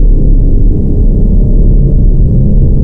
1 channel
AmbDroneQ.wav